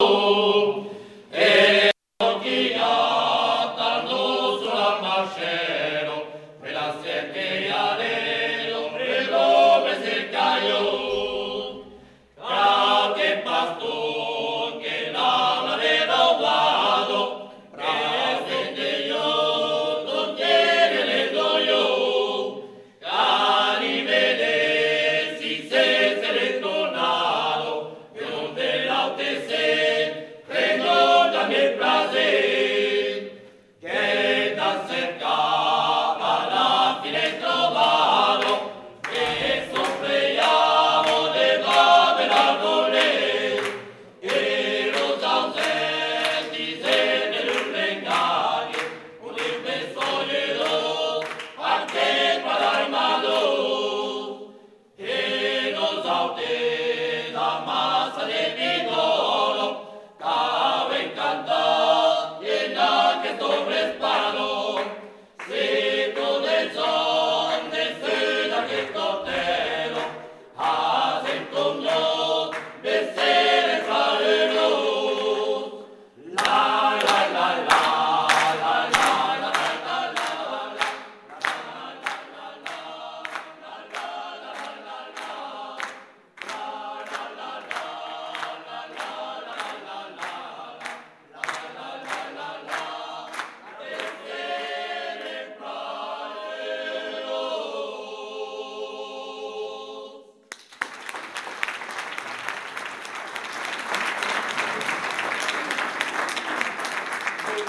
Eths amassats de Bigòrra (ensemble vocal)
Aire culturelle : Bigorre
Genre : chant
Type de voix : voix d'homme
Production du son : chanté ; fredonné
Descripteurs : polyphonie
Notes consultables : Variations de niveau sonore.